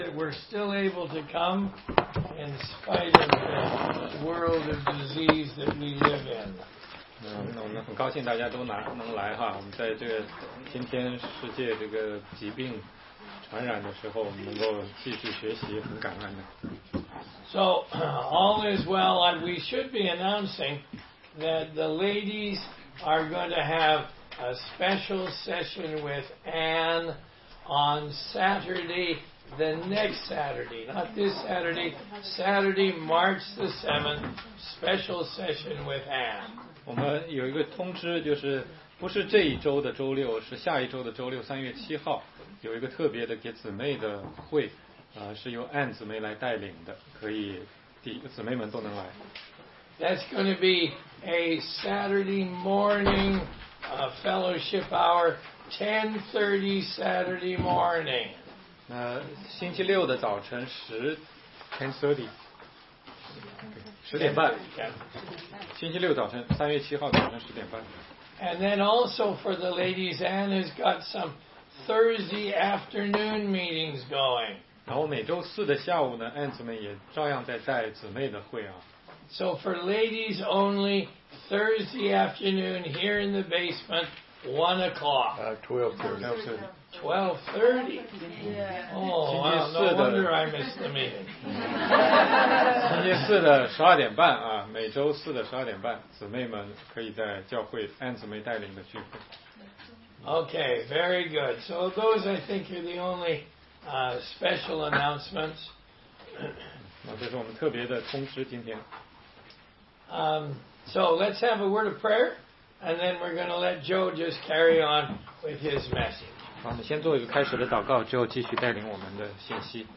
16街讲道录音 - 其它